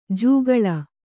♪ jūgaḷa